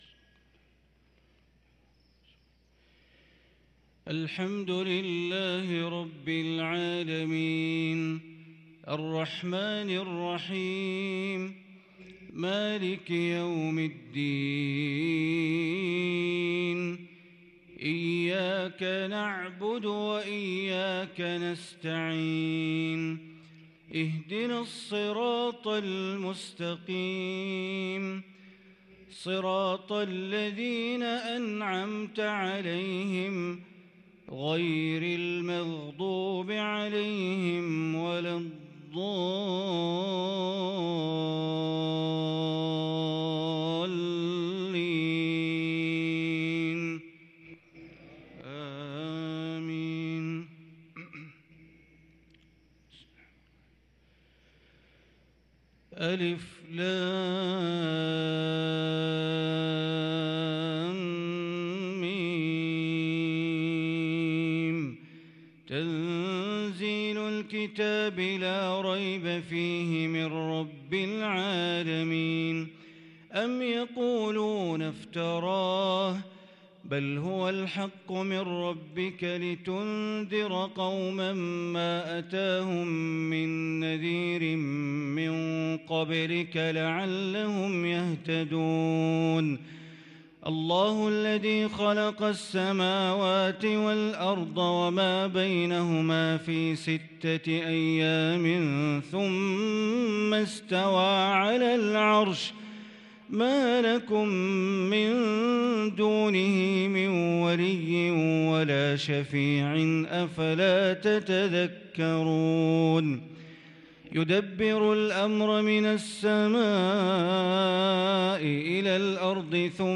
صلاة الفجر للقارئ بندر بليلة 5 شوال 1443 هـ
تِلَاوَات الْحَرَمَيْن .